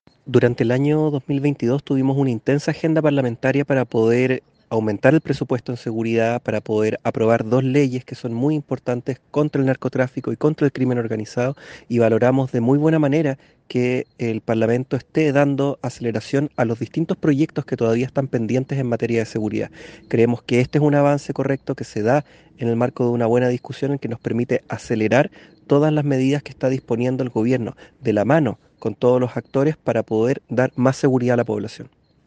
Tras la aprobación, el Delegado Presidencial Regional, Rubén Quezada, destacó que